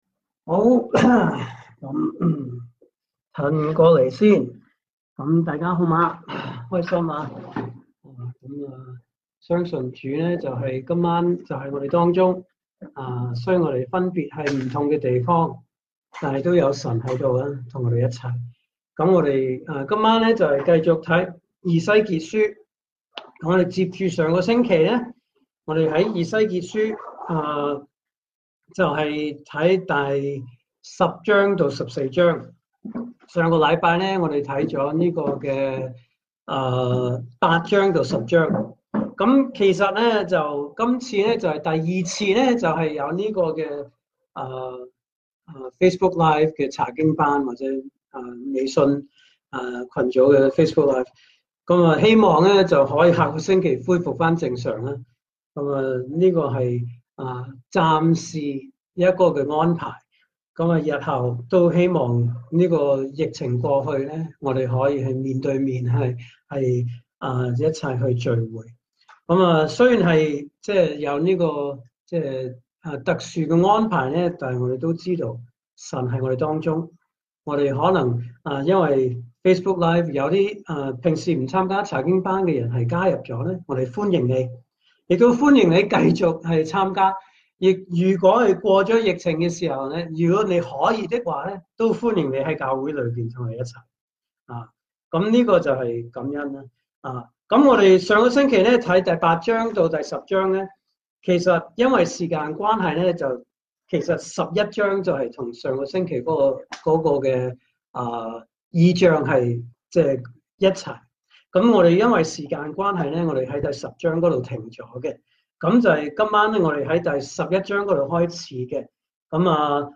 證道信息